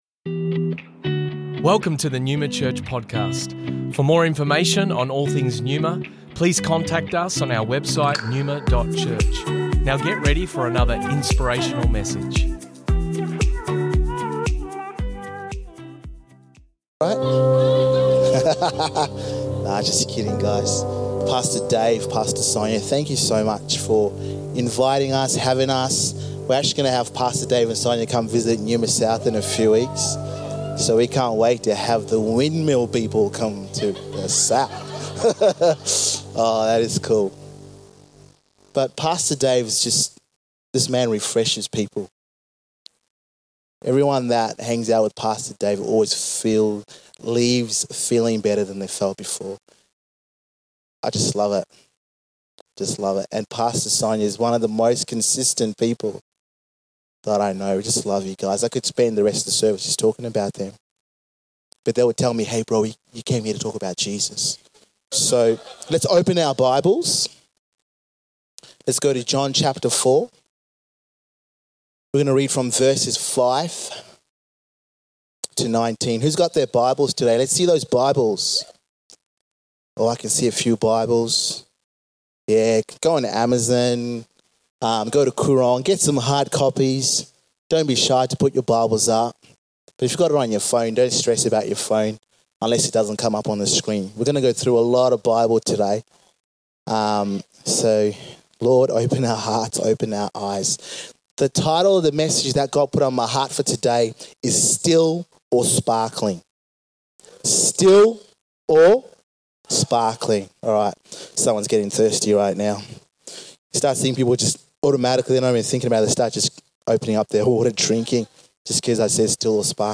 Originally recorded at Neuma Melbourne West 22nd October 2023